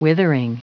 Prononciation du mot withering en anglais (fichier audio)
Prononciation du mot : withering